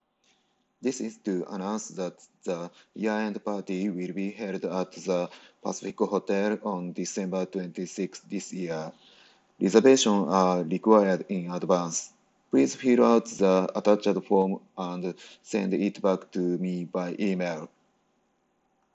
確認方法はそれぞれのヘッドセットをパソコンにBluetooth接続した状態で、Windows10に標準装備されているボイスレコーダに音声を録音して聞き比べてみます
今回、Bose SoundSport wireless headphonesとPlantronics explorer-100は特にノイズは無く会話するには十分な音質で、違いもほとんどわかりませんでした。